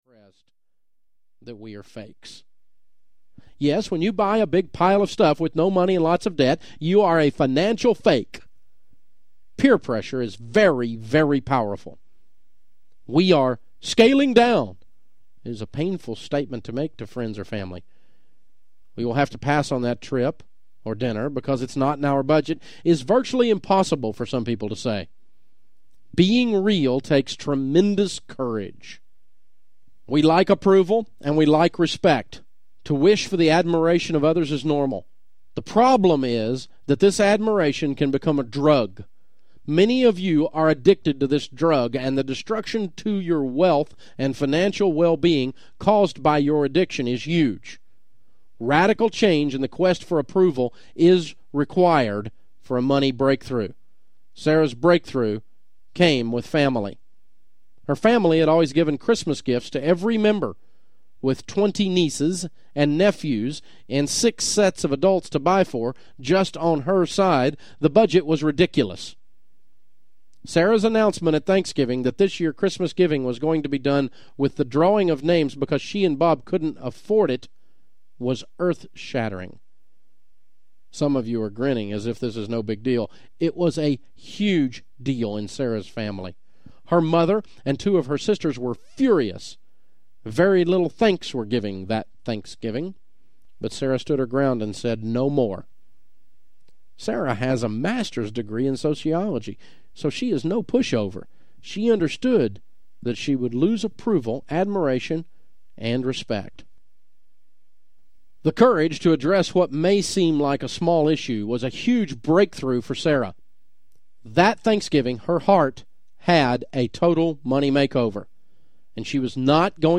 The Total Money Makeover Audiobook
Narrator